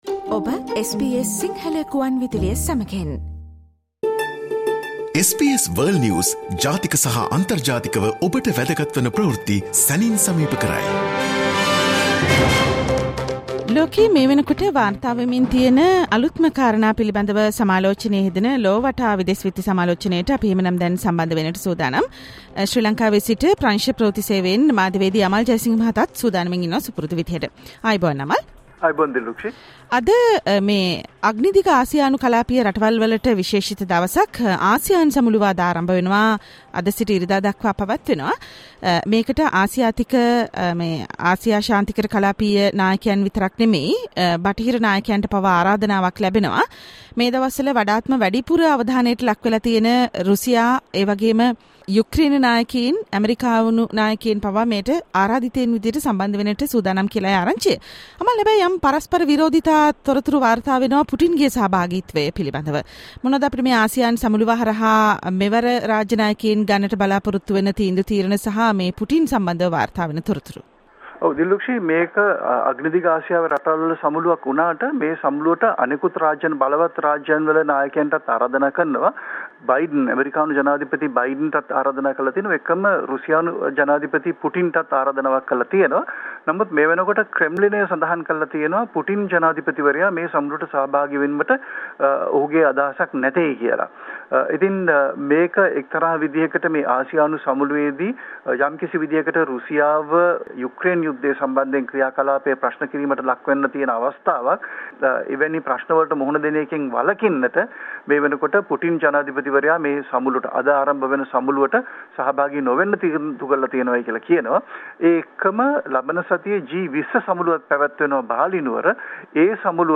and the world news critic World's prominent news highlights in 13 minutes - listen to the SBS Sinhala Radio weekly world News wrap every Friday Share